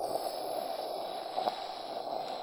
snd_flames.wav